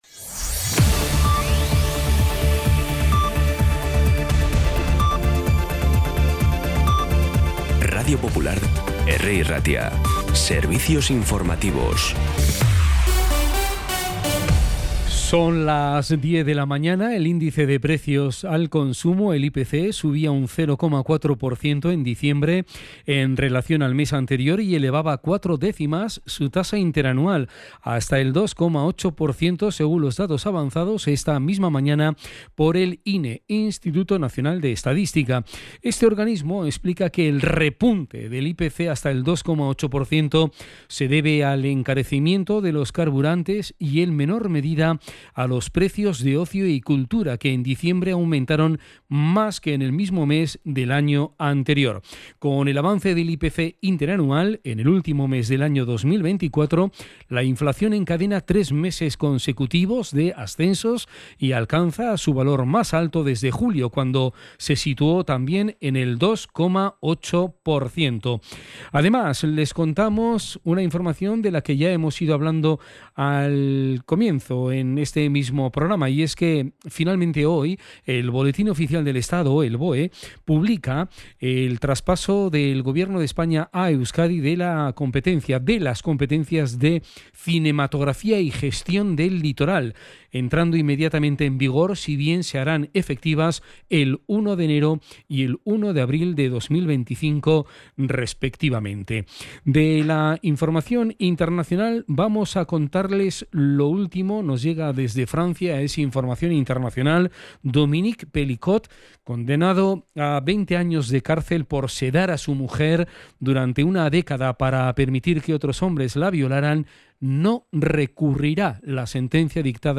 Las noticias de Bilbao y Bizkaia del 30 de diciembre a las 10
Los titulares actualizados con las voces del día. Bilbao, Bizkaia, comarcas, política, sociedad, cultura, sucesos, información de servicio público.